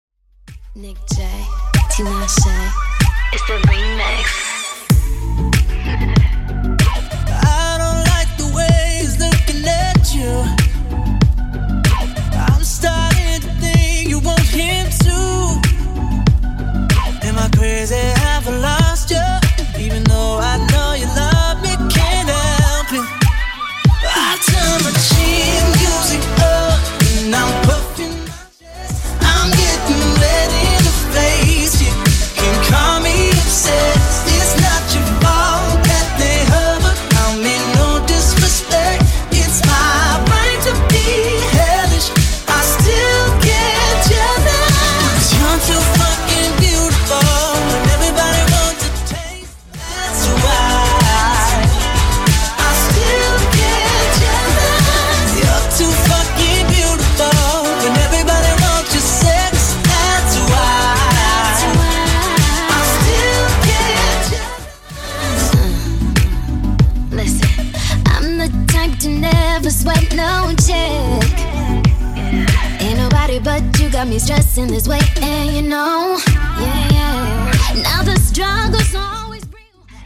BPM: 95 Time